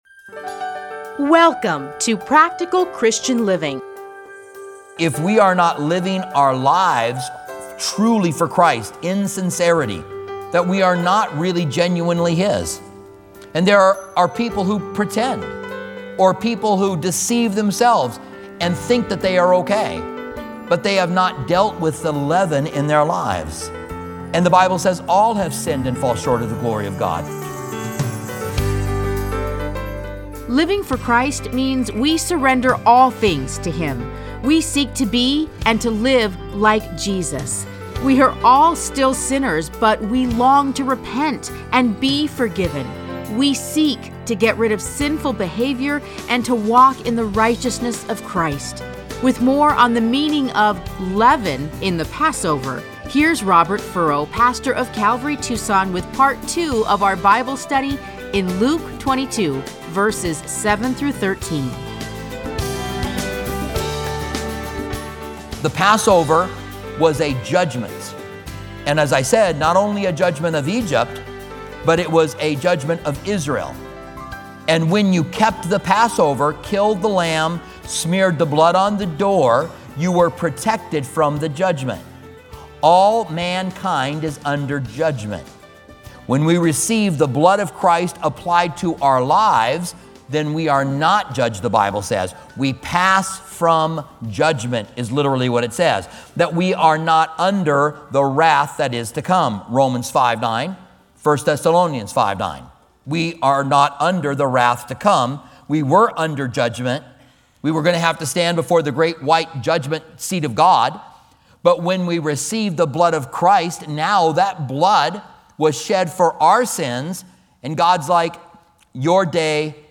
Listen to a teaching from Luke 22:7-13.